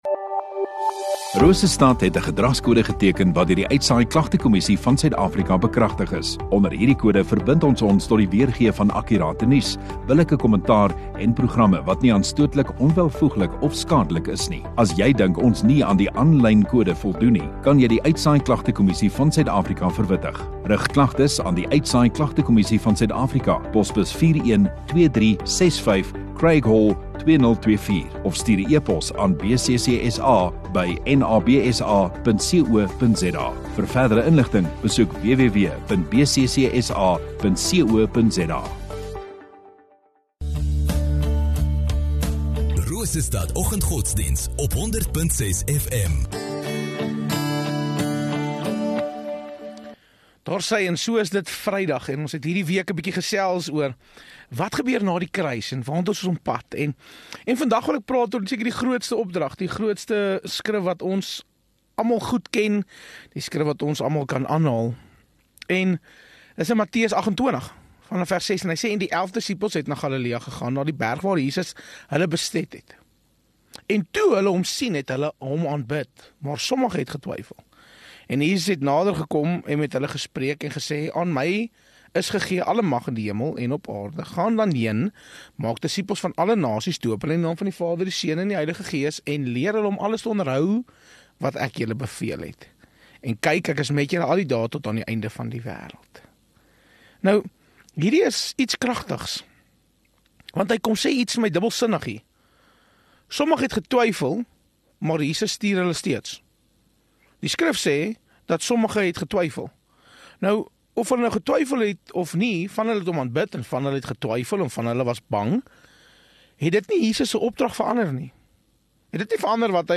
Rosestad Godsdiens